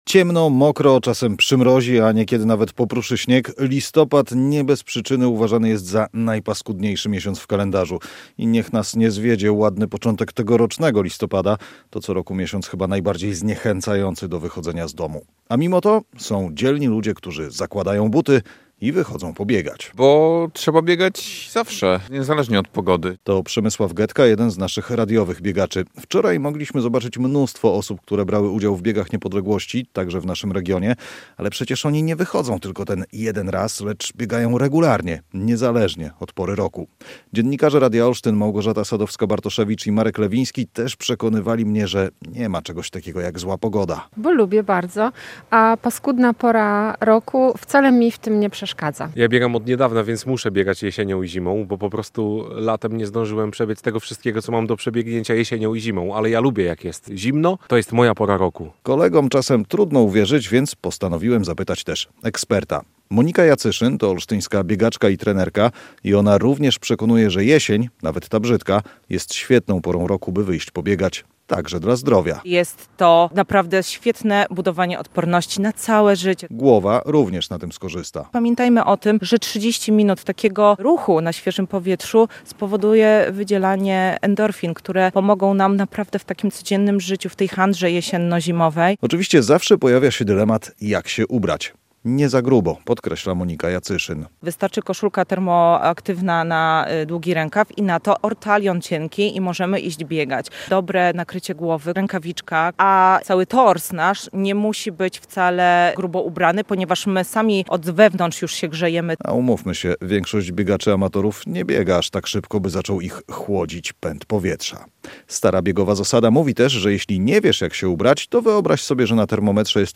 olsztyńska biegaczka i trenerka